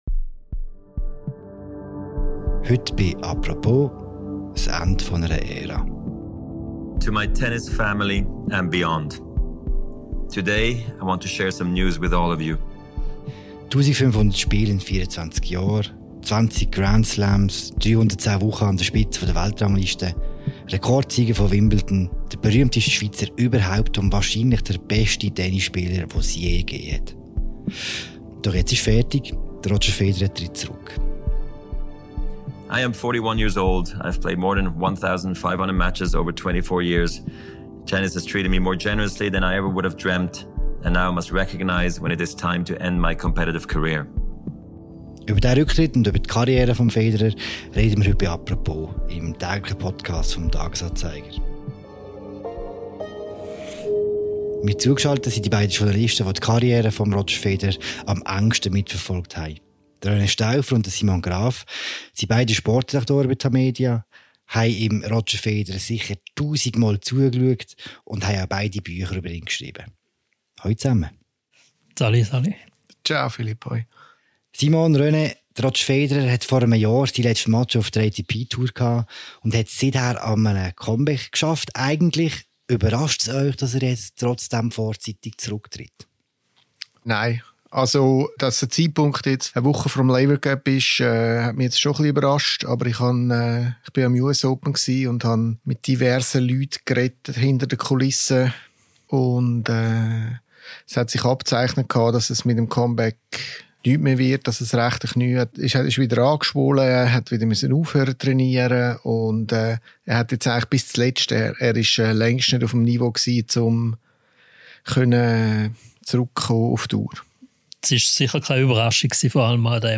Es ist tatsächlich geschehen: Roger Federer tritt zurück. Zwei Journalisten, die ihn während seiner ganzen Karriere begleitet haben, blicken zurück.